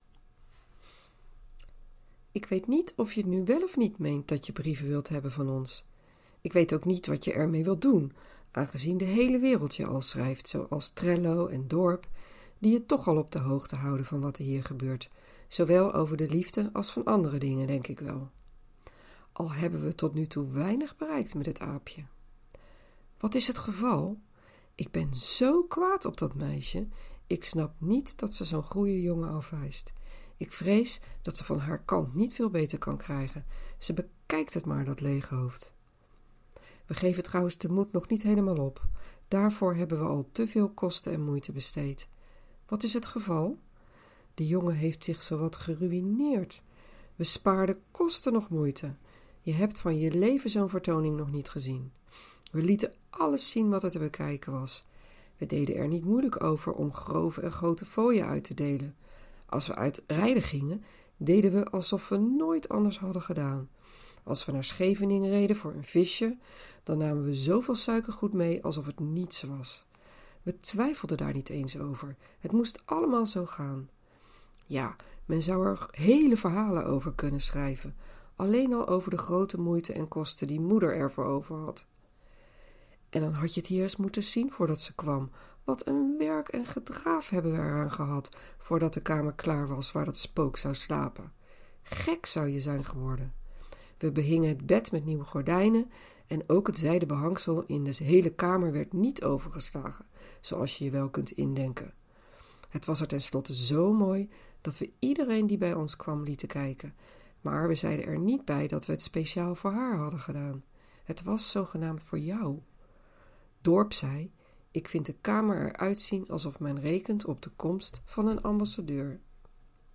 Een reconstructie in woord en geluid
Niet alleen letterlijk, maar we reconstrueren ook hoe Geertruyds uitspraak geklonken moet hebben.